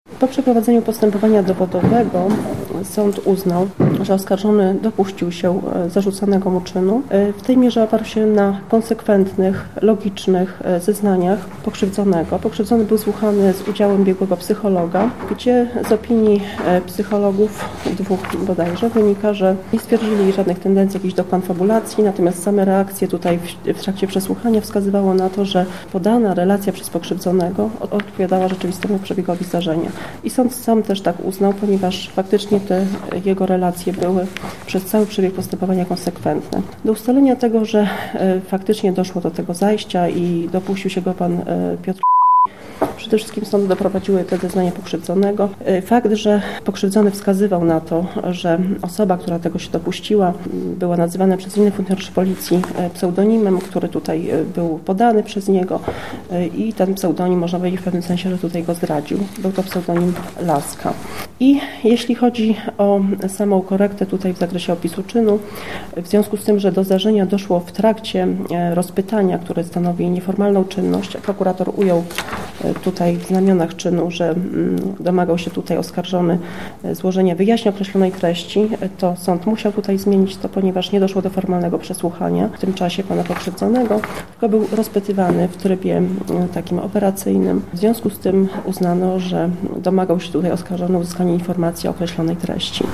Wyrok wygłosiła sędzia Anna Dąbrowska